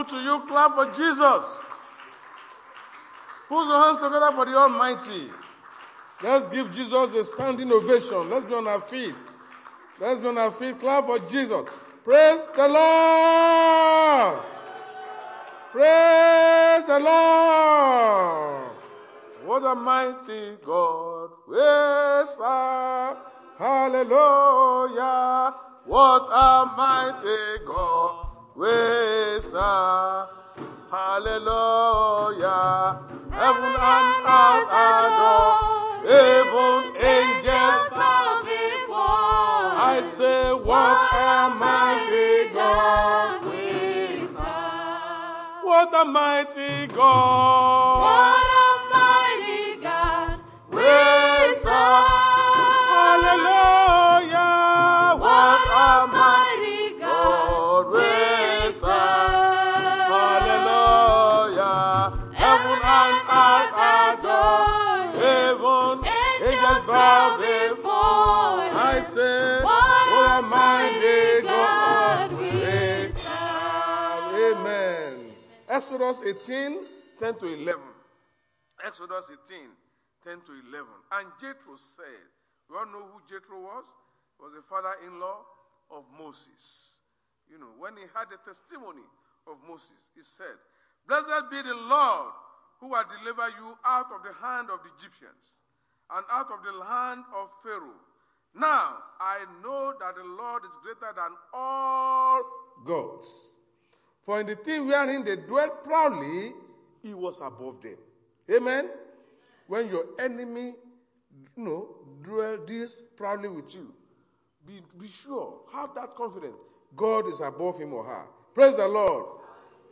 Sunday Sermon- Chosen For Salvation
Service Type: Sunday Church Service